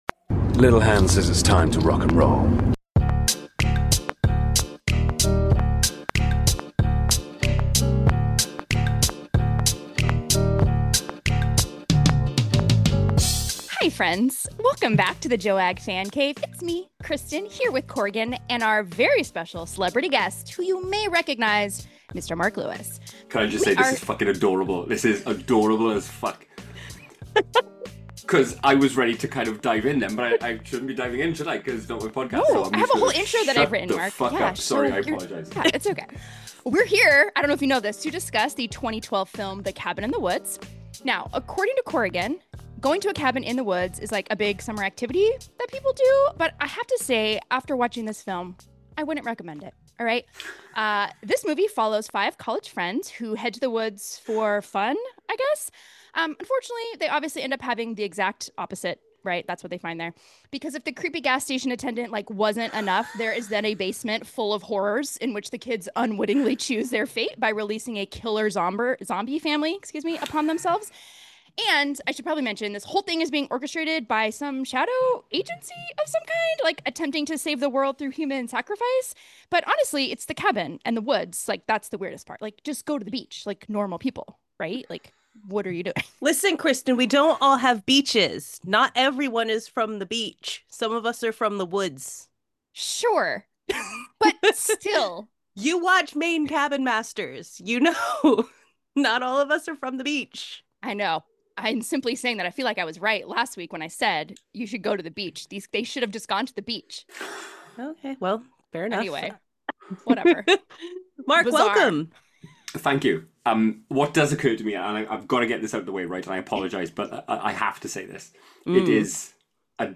A storytelling pod in which an American girl and a Welsh guy bond over a shared love of the terrifying, tragic, bloody, morbid, anxiety-inducing, and horrific -- a joyful and therapeutic exploration of very dark things.